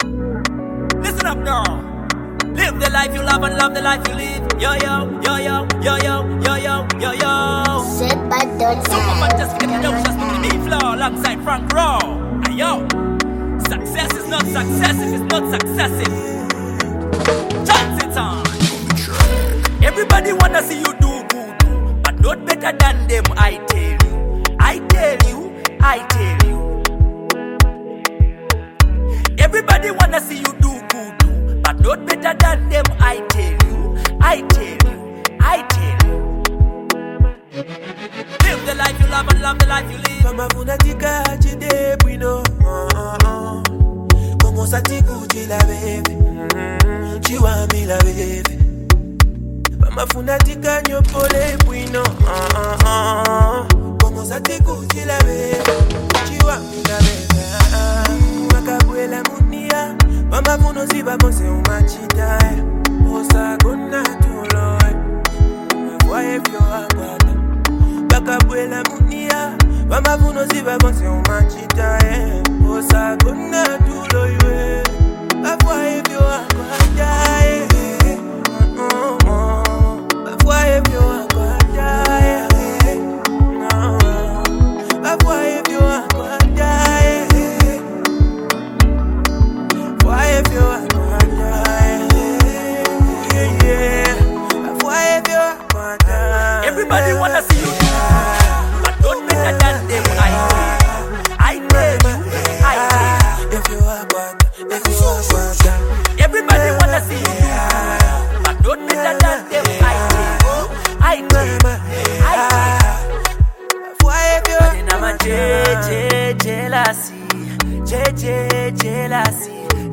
The beat is infectious, with a rhythm that keeps you moving.